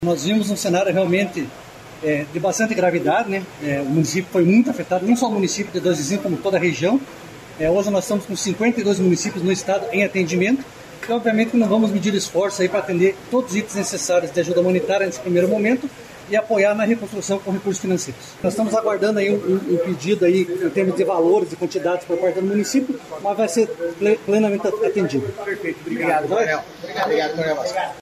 Sonora do coordenador da Defesa Civil Estadual, Fernando Schunig, sobre as enchentes em Dois Vizinhos e em todo o Paraná